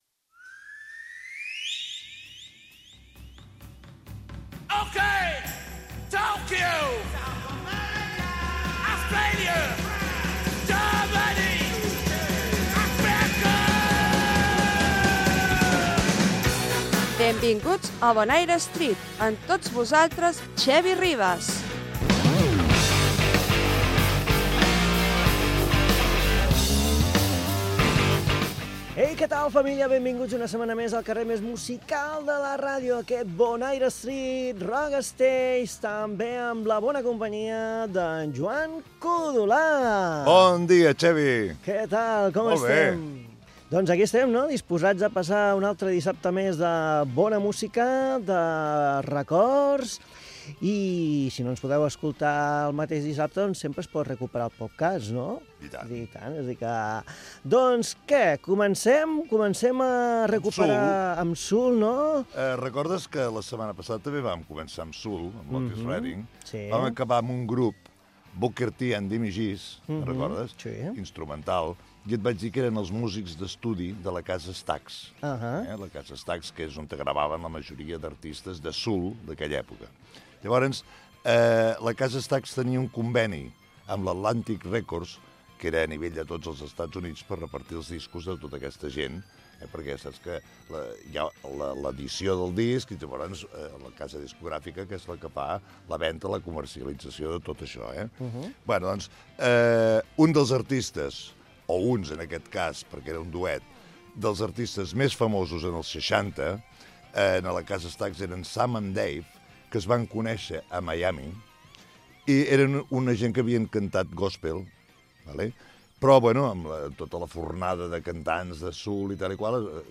Sintonia, presentació del programa. Comentari sobre una discogràfica dels EE.UU. i tema musical
Musical
FM